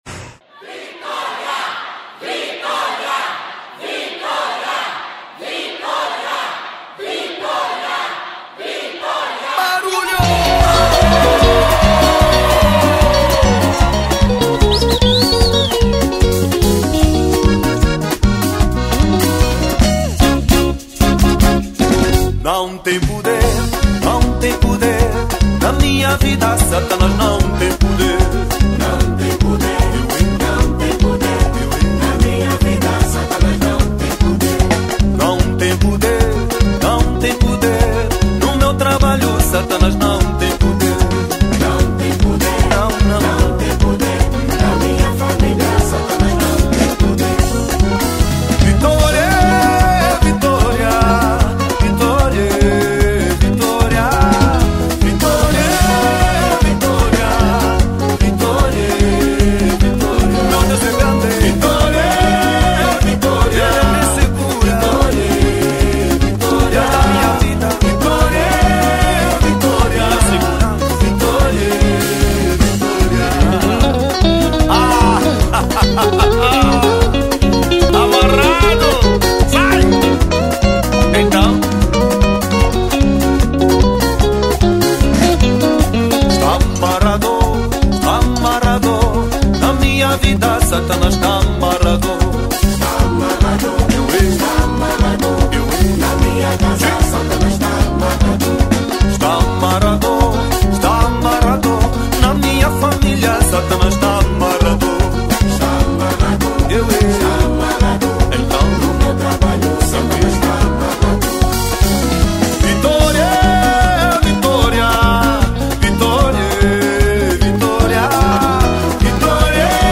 Gospel 2023